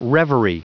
Prononciation du mot reverie en anglais (fichier audio)
Prononciation du mot : reverie